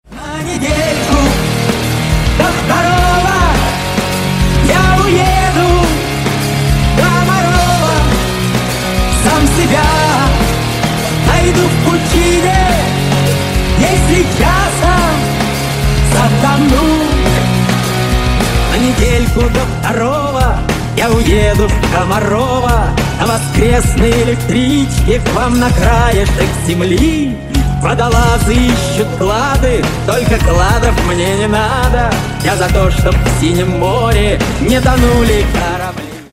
Рингтоны Ремиксы » # Рок Металл